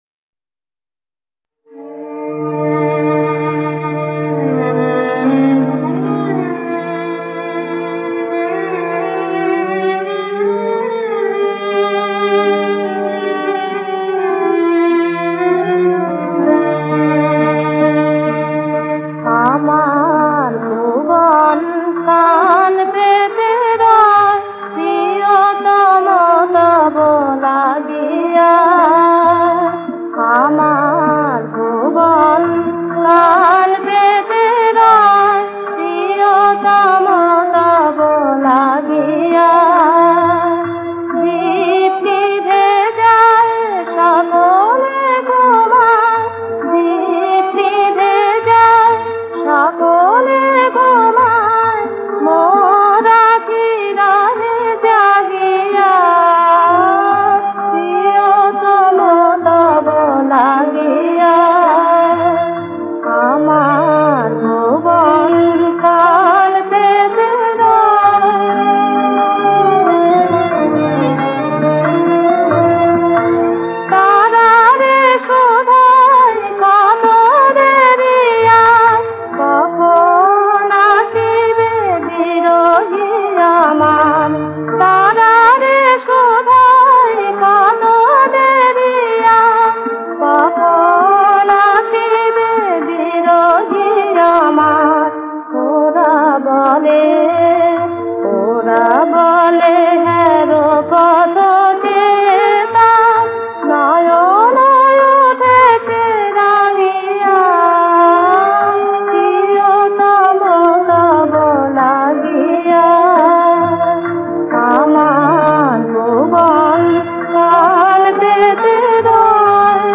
নজরুল সঙ্গীত